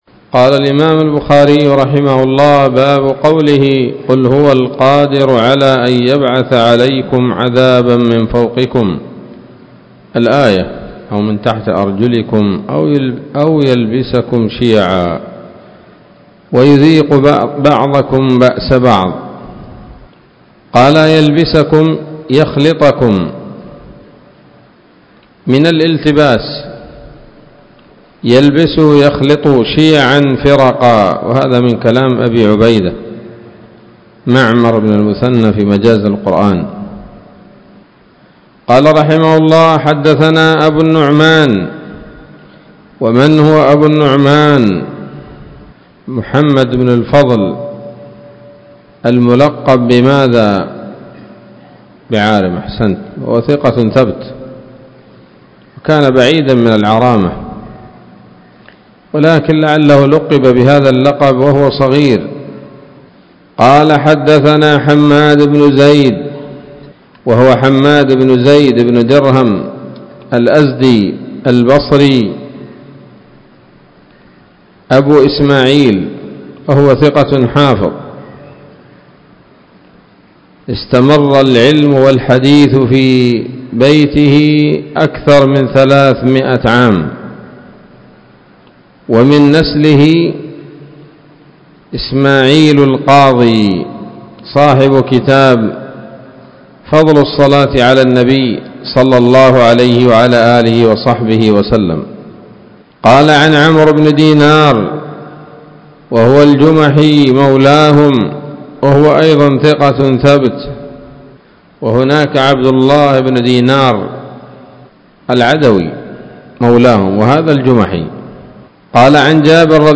الدرس المائة من كتاب التفسير من صحيح الإمام البخاري